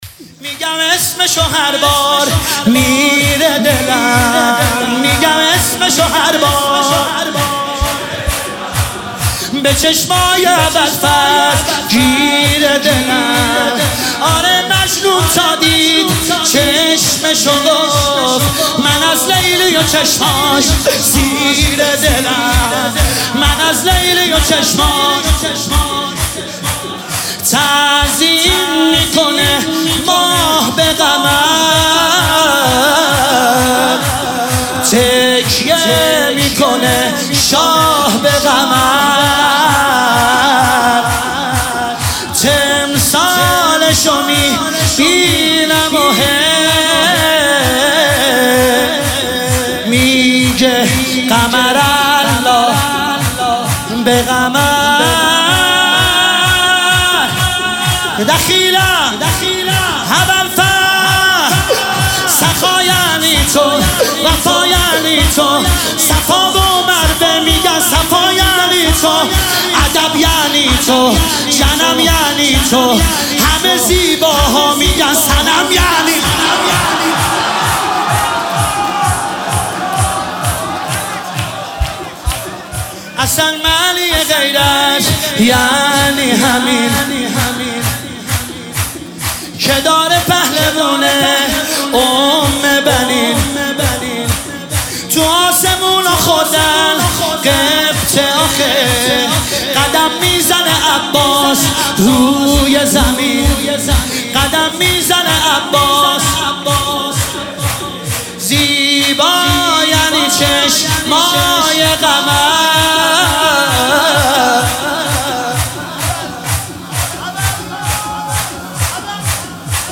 شهادت امام جواد (ع) 1404